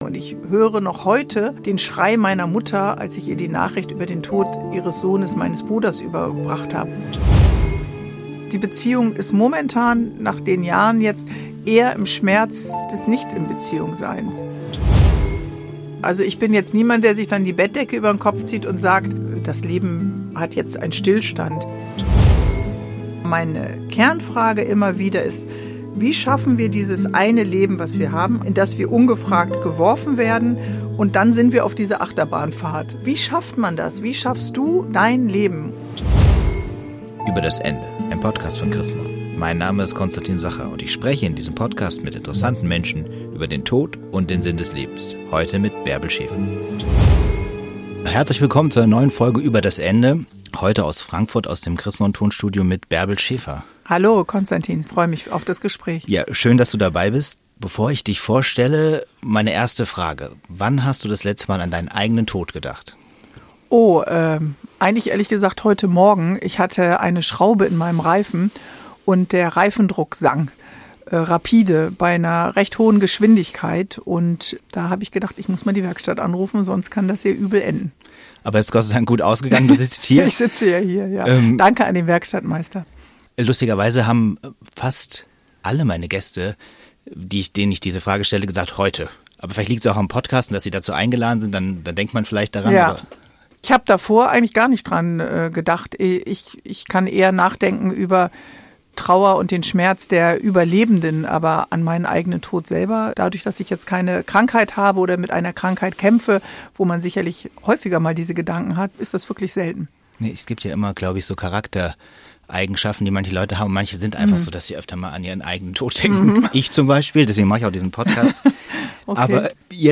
Das alles macht sie zu einer perfekten Gesprächspartnerin für die zwölfte Folge von "Über das Ende".